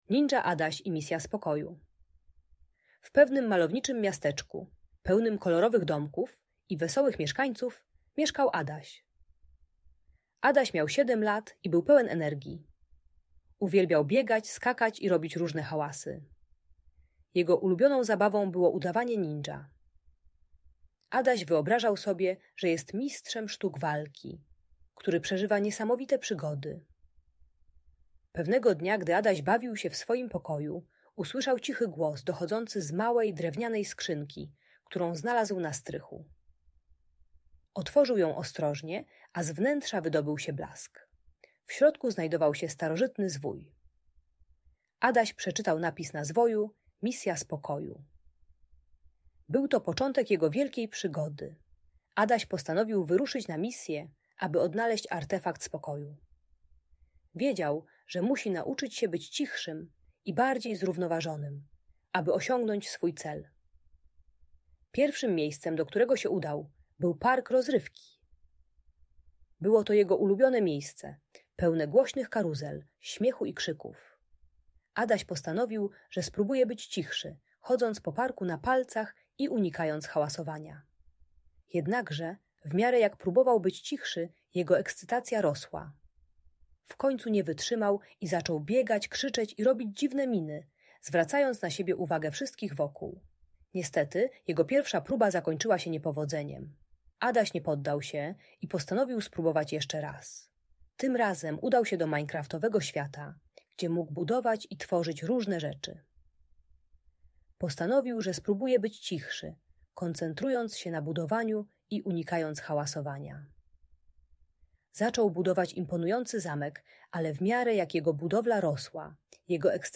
Ninja Adaś i Misja Spokoju - Audiobajka